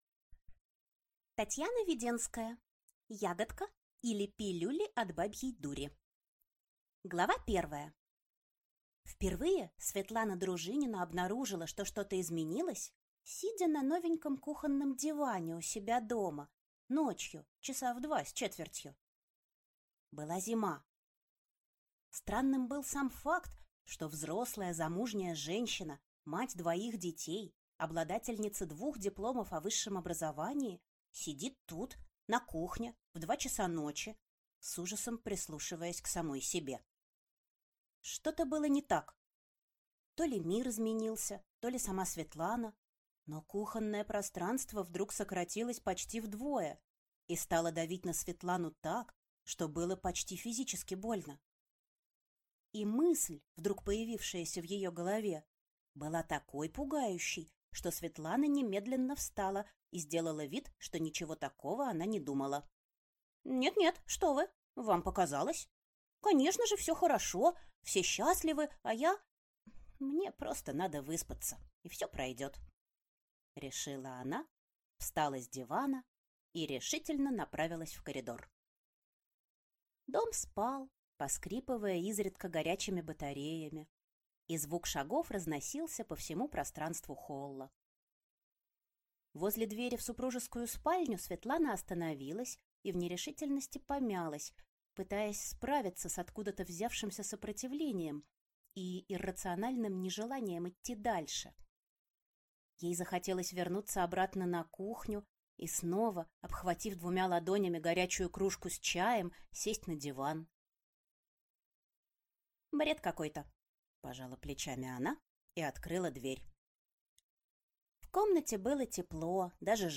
Аудиокнига Ягодка, или Пилюли от бабьей дури | Библиотека аудиокниг
Прослушать и бесплатно скачать фрагмент аудиокниги